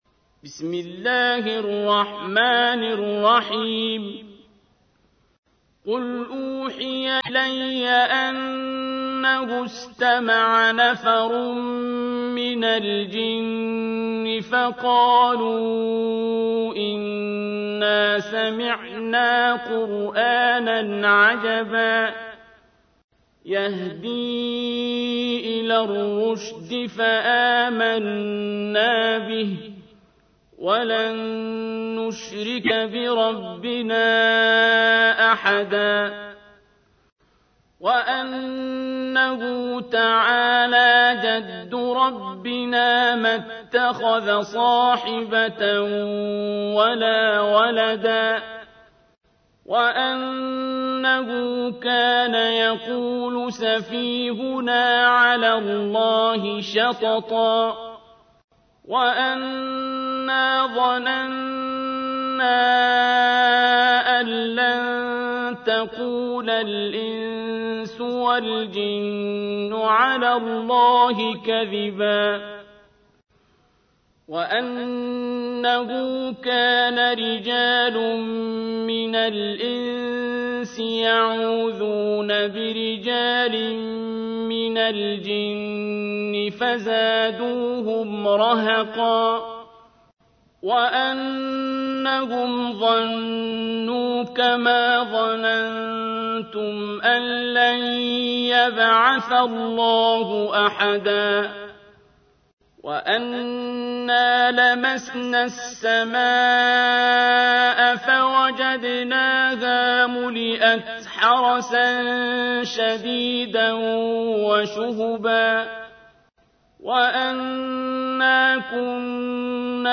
دانلود سوره جن صوتی با صدای عبدالباسط
abdulbasit - jinn surah [320].mp3